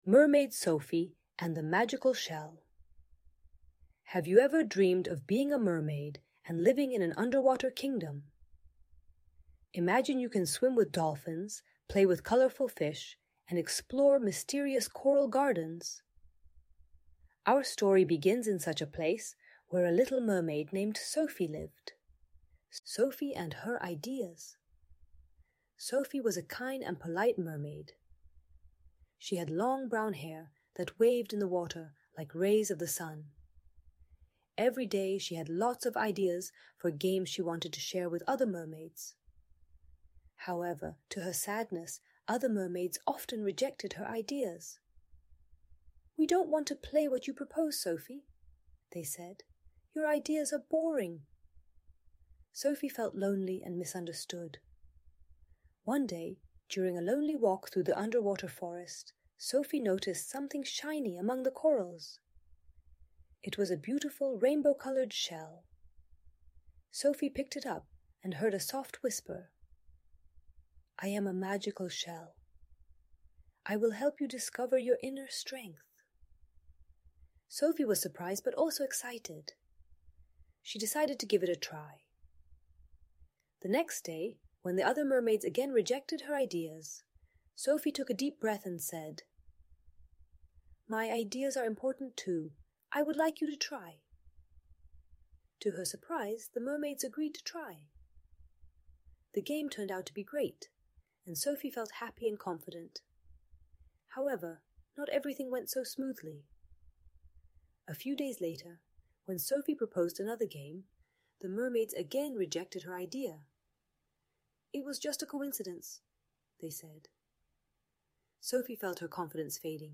Opowieść o syrenie Sophie i magicznej muszli - Lęk wycofanie | Audiobajka